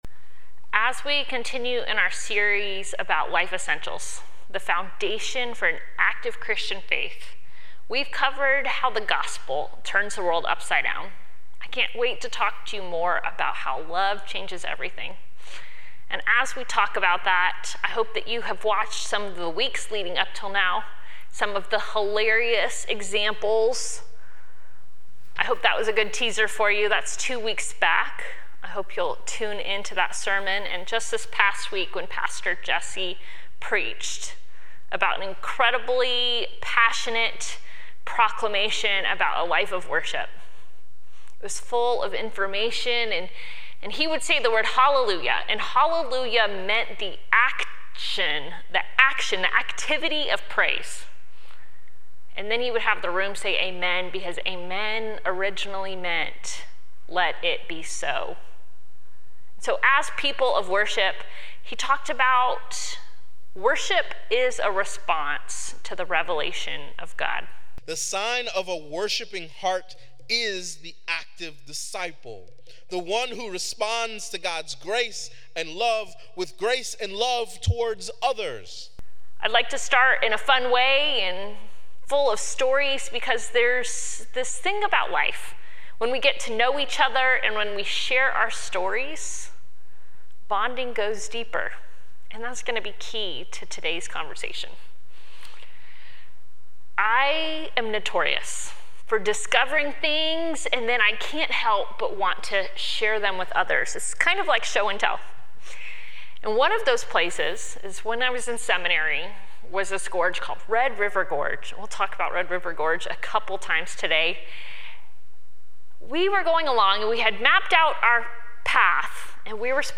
This week, we explore John Wesley's Means of Grace. Sermon Reflections: In what ways have I allowed my spiritual practices to evolve and grow over time?